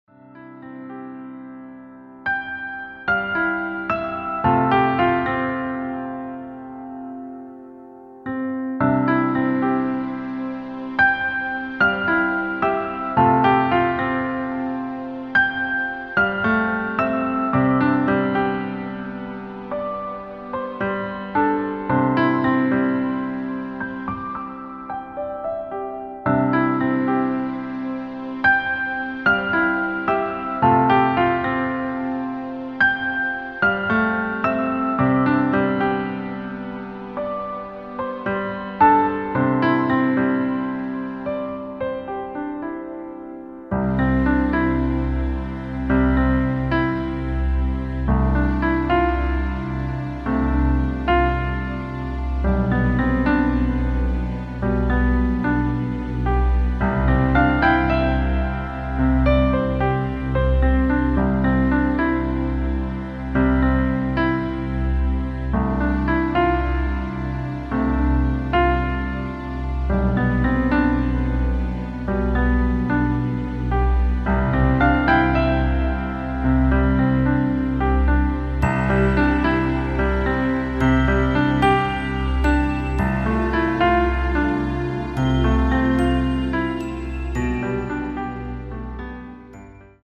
• Das Instrumental beinhaltet NICHT die Leadstimme
Klavier / Streicher